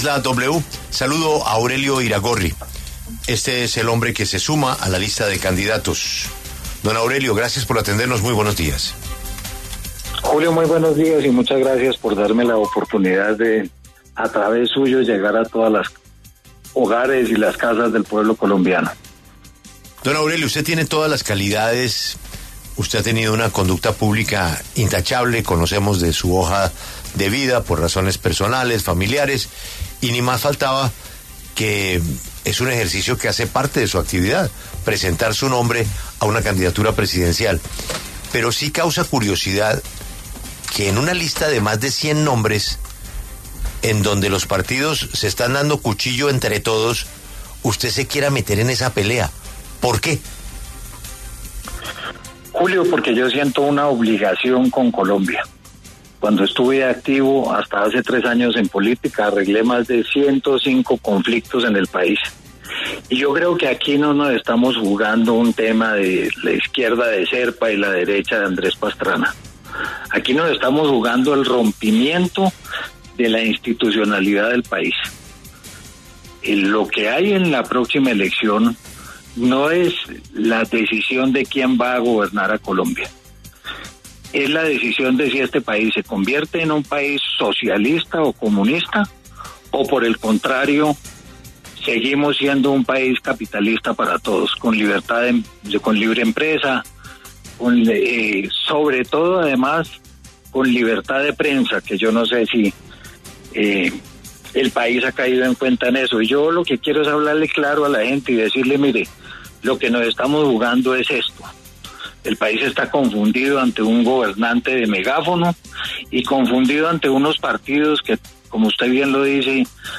Aurelio Iragorri, aseguró, en los micrófonos de La W, que buscará el aval de un partido, dejando claro que no será el de la U.